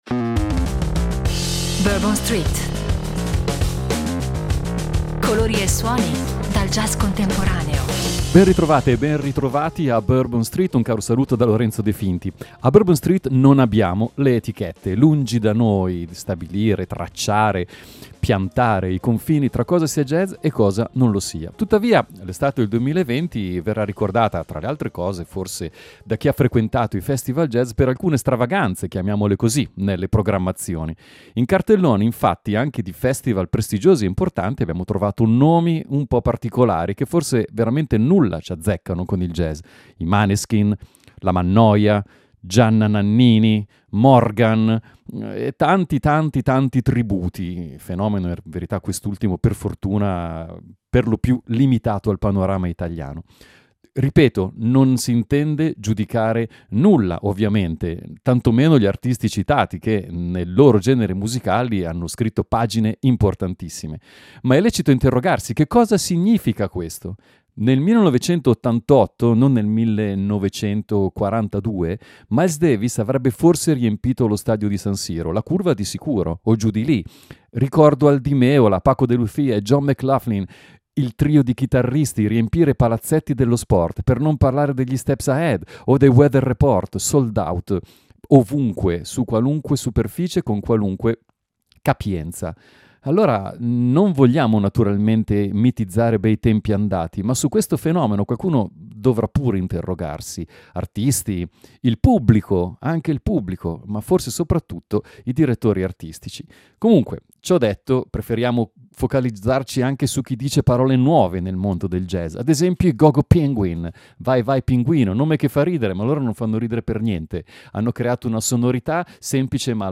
Vecchio e nuovo insieme, spinta verso il futuro coniugata con il più totale rispetto della propria storia: colori e suoni dal jazz contemporaneo insomma.